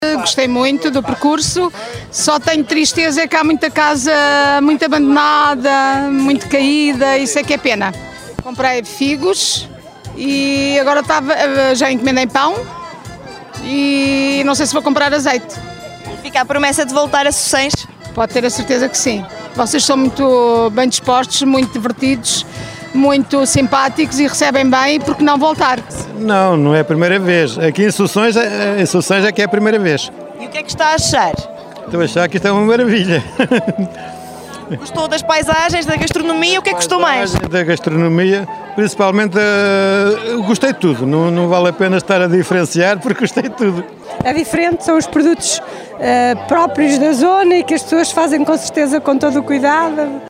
Os visitantes renderam-se aos ex-libris da aldeia. Estes que nos falam, chegaram de Lisboa, para participar num percurso pedestre inserido no programa desta IV Feira do Pão e do Azeite.